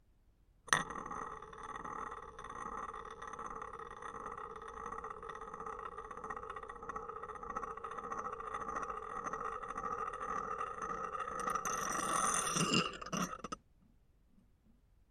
Звук крутящегося игрушечного волчка на стекле